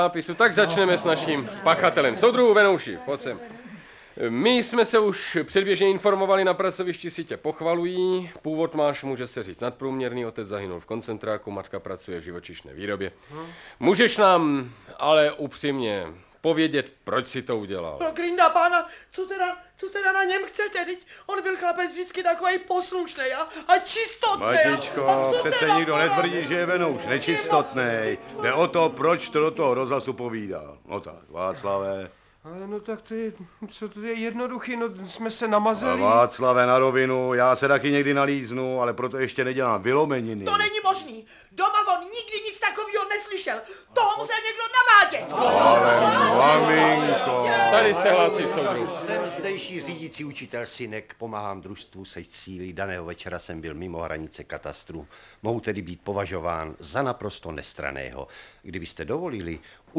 Audiobook
Audiobooks » Short Stories
Read: Antonie Hegerlíková